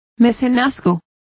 Complete with pronunciation of the translation of patronage.